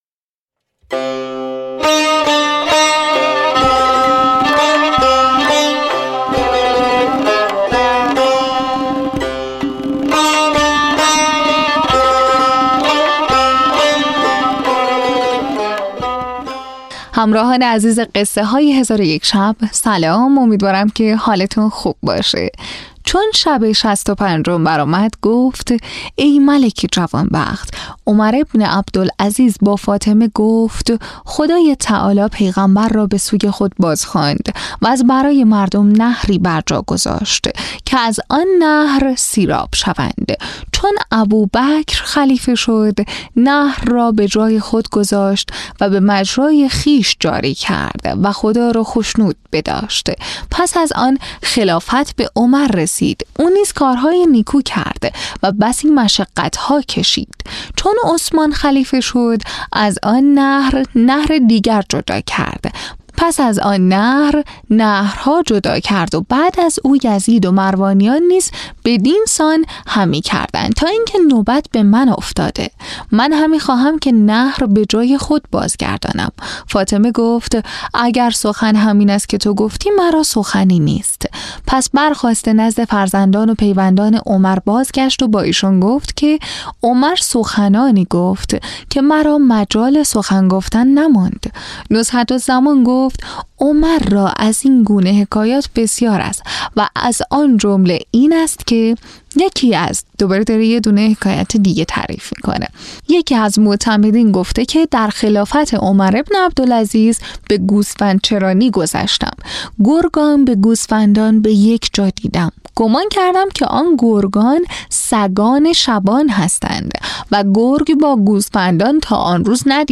تهیه شده در استودیو نت به نت